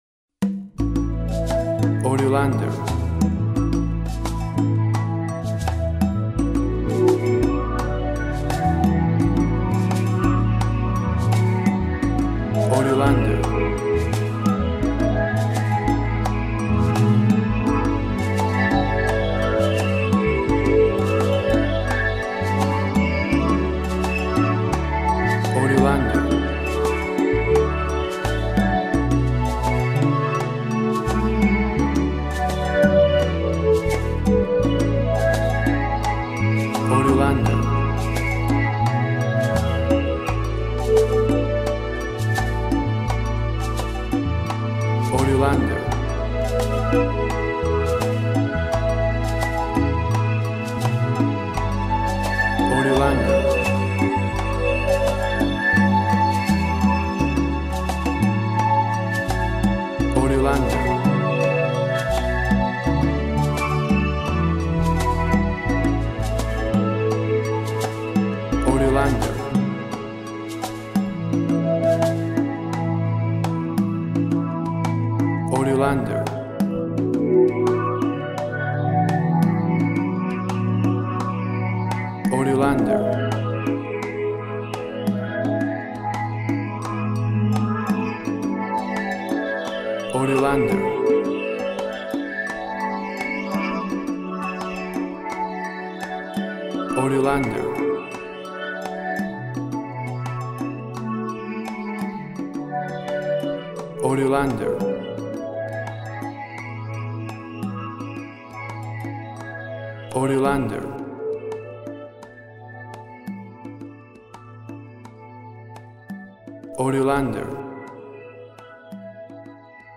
Bahia Brazilian Rythms.
Tempo (BPM) 70